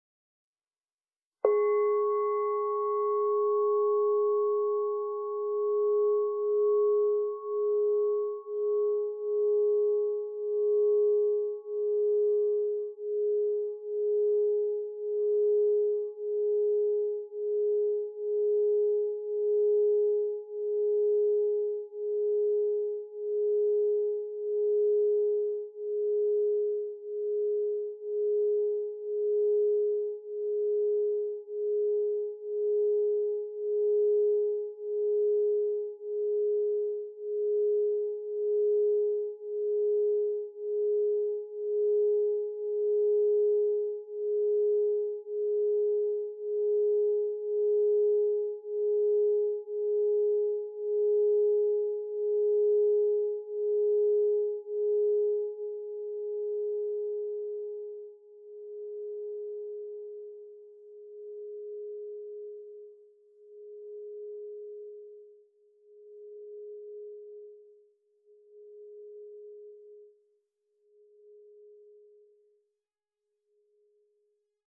Meinl Sonic Energy 5,75" Essence Solfeggio Crystal Singing Bowl Re 417 Hz, Kupferbraun (ESOLCSB417)